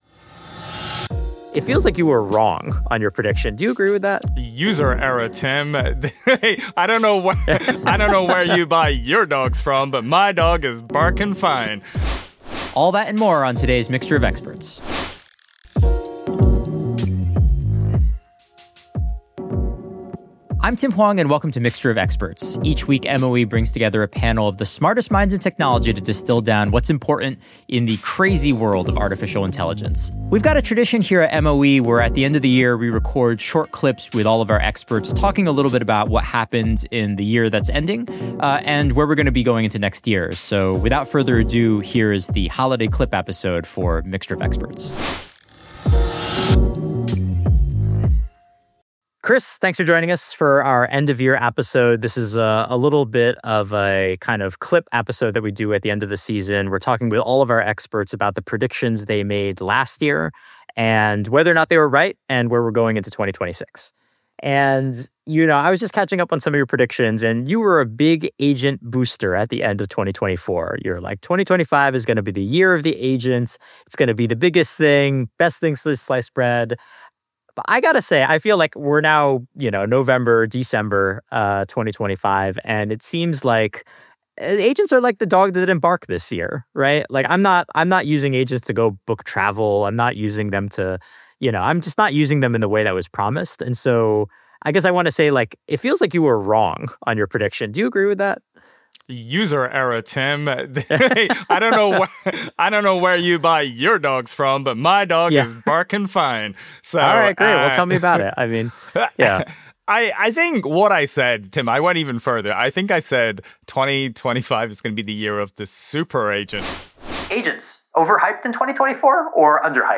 sits down with veteran panelists to review the biggest AI moments of 2025 and make bold predictions for the year ahead.